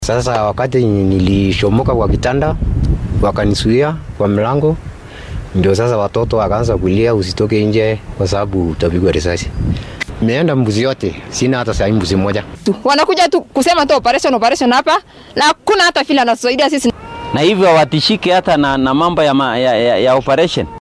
Mid ka mid ah dadka deegaanka ee xoolaha laga dhacay ayaa warbaahinta la hadlay